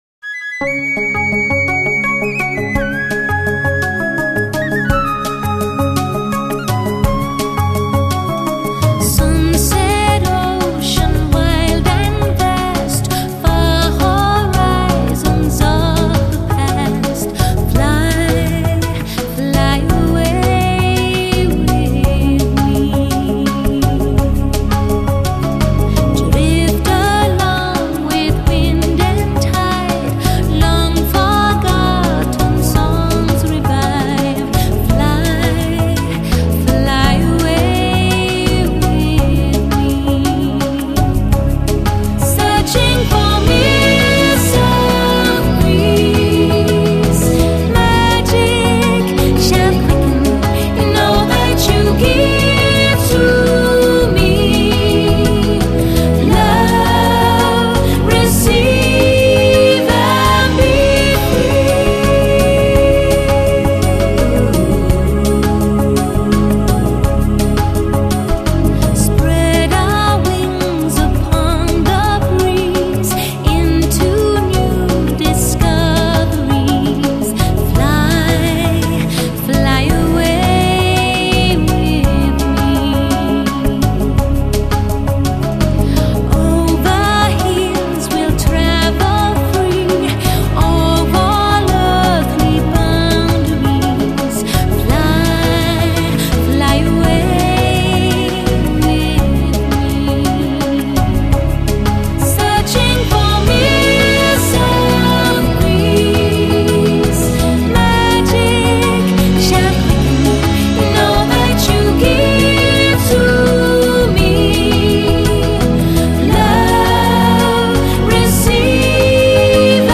演唱歌手：新世纪音乐